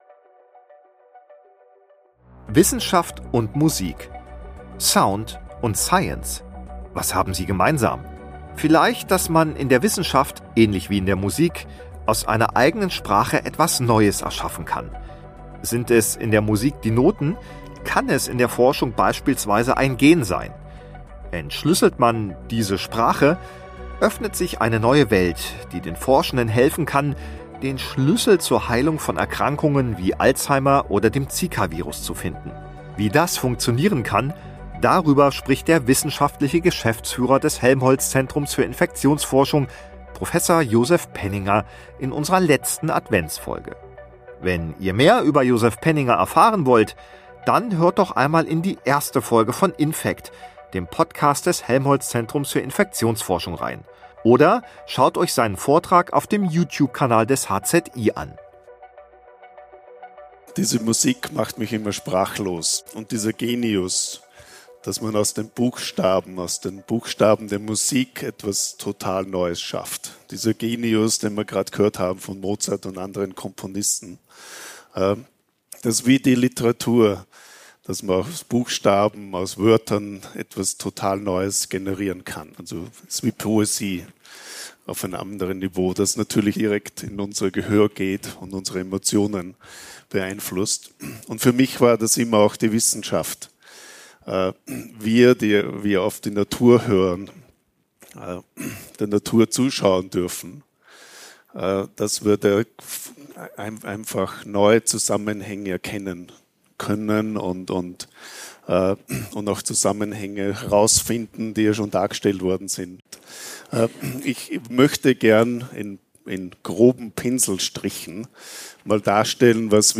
MODERATION 1.
STREICHQUARTETT: 2. Rainer Honeck (Violine) 3.
(Viola)
(Kontrabass)